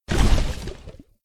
default_lava_footstep.2.ogg